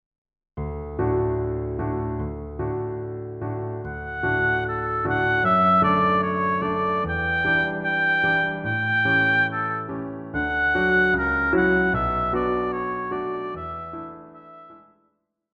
古典
雙簧管
鋼琴
演奏曲
獨奏與伴奏
有節拍器
Here we have an arrangement for oboe and piano.